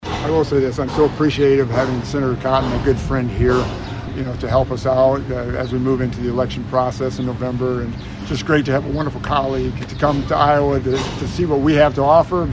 Feenstra and Cotton made their comments at Scranton Manufacturing following their tour.